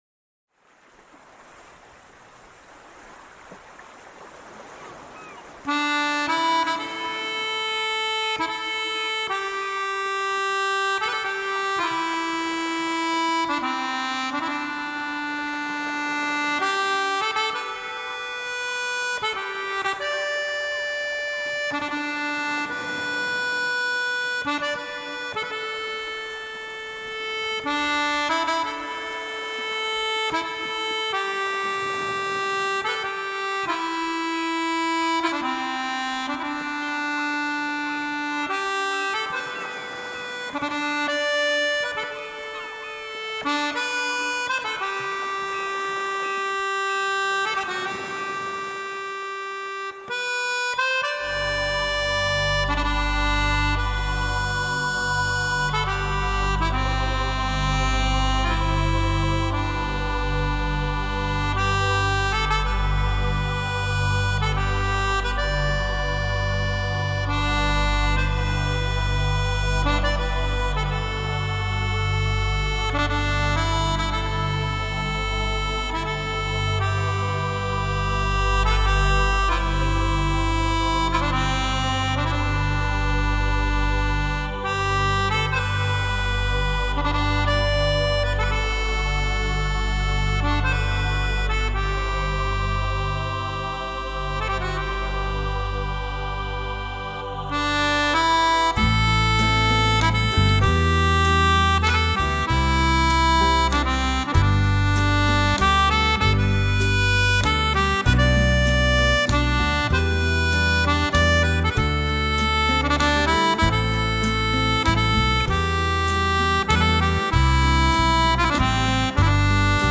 Genre: International.